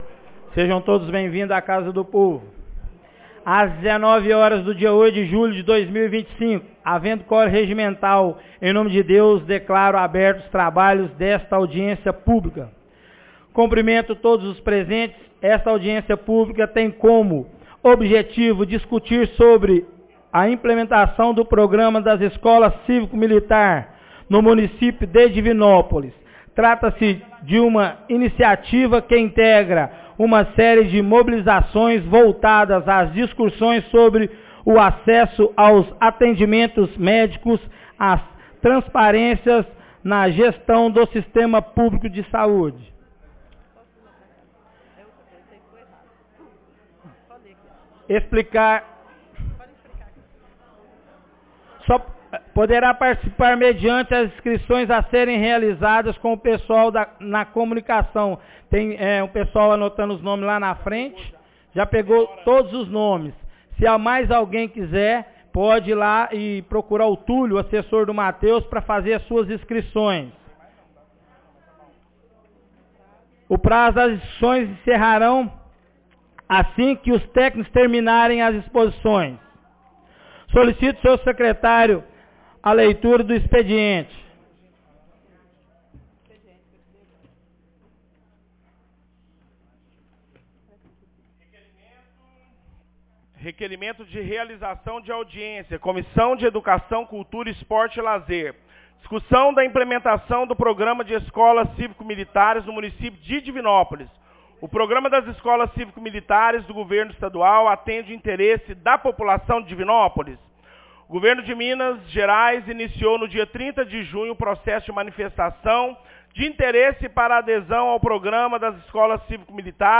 Audiencia Publica dicussao de implementação das Escola civico militantes em Divinopolis 08 de julho de 2025